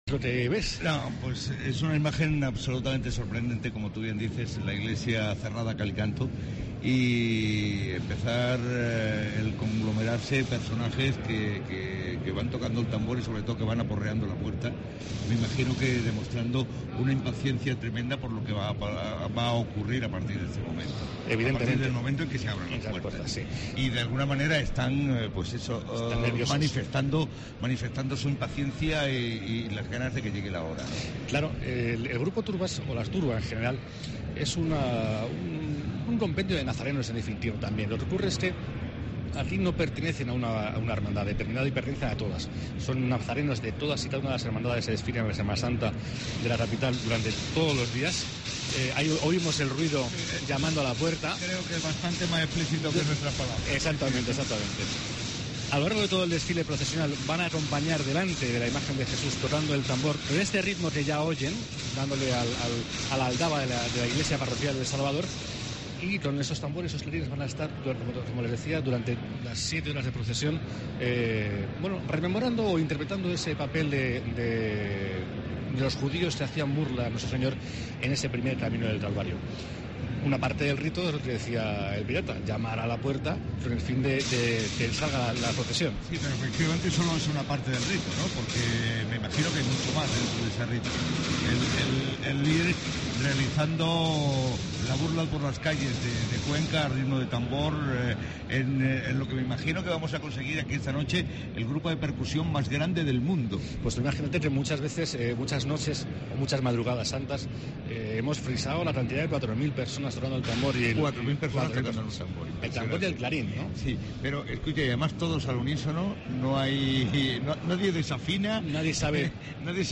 AUDIO: La Voz de la Pasión, retransmisión Camino del Calavario de 04.30 a 05.00 horas